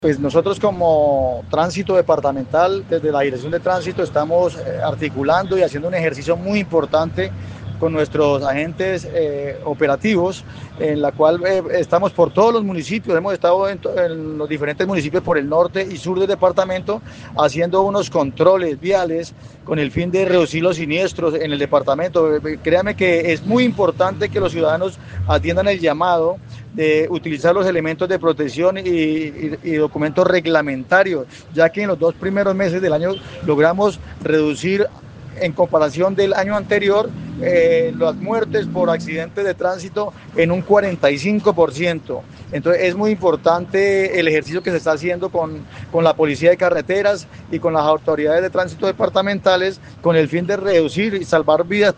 Hernán Castañeda Suarez, director de tránsito seccional, explicó que, a través de puestos de control ubicados en carreteras del norte y sur del departamento, se insta a conductores de vehículos y motocicletas a portar los elementos de seguridad y documentación legal exigida, con el fin de evitar la siniestralidad vial.